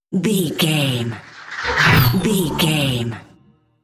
Horror whoosh electronic
Sound Effects
Atonal
ominous
suspense
eerie